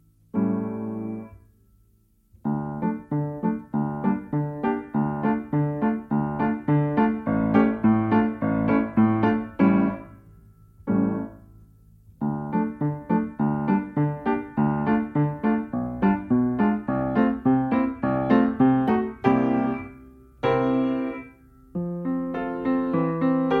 Nagranie bez metronomu, uwzględnia rubata.
Allegro moderato II: 96 bmp
Nagranie dokonane na pianinie Yamaha P2, strój 440Hz